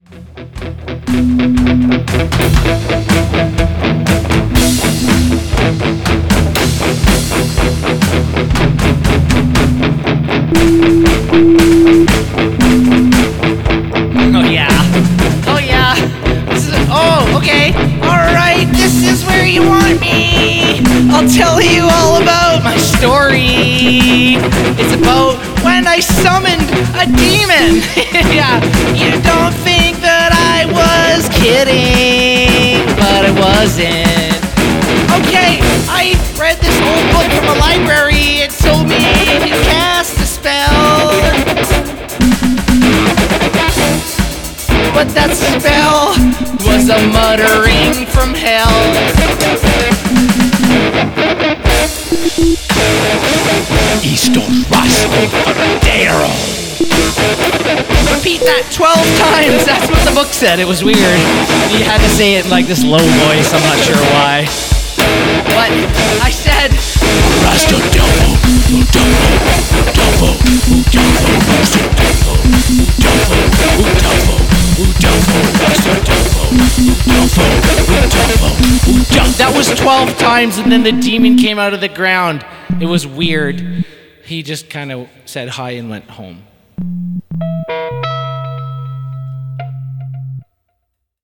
Main Vocals
Drums
Electric Guitar
Synthesizer
Hard Rock Story 1m31s Nov 8th, 2025 (Nov 15th, 2025)